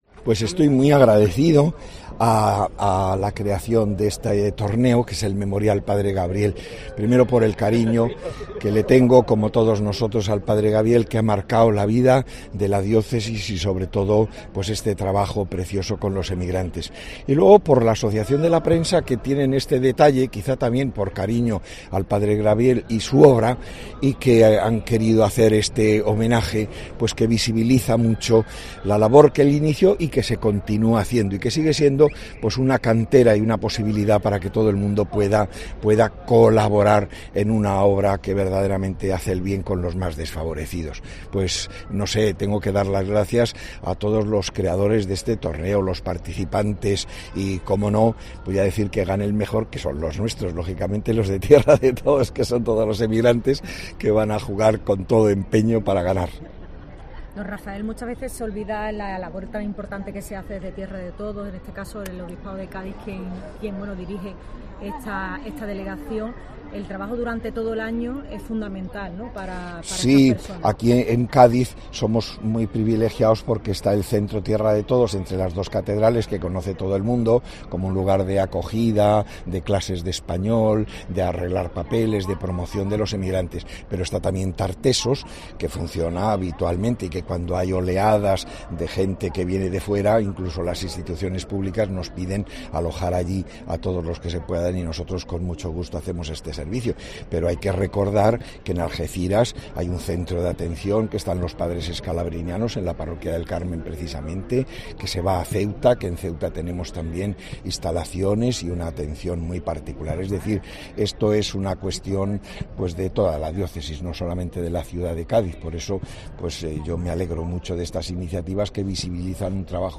Monseñor Rafael Zornoza, obispo de Cádiz y Ceuta, habla del I Torneo Tierra de Todos